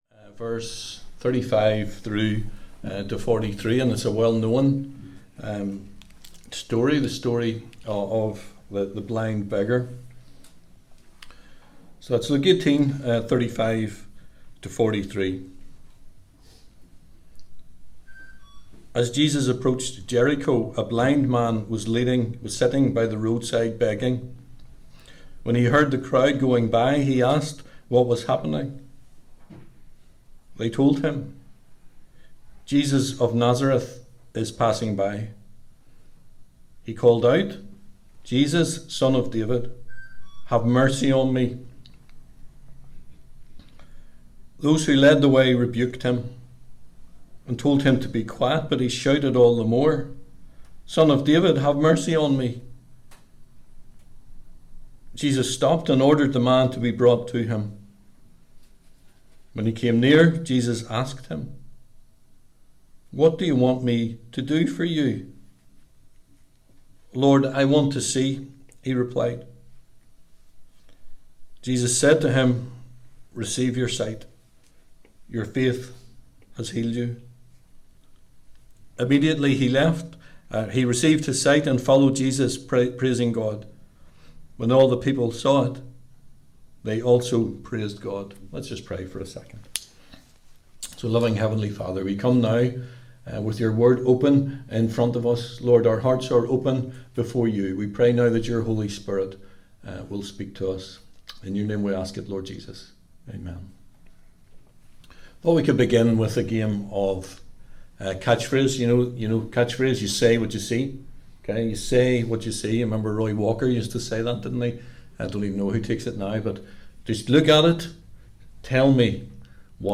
Service Type: 11am Topics: Perception , Persistance , Precision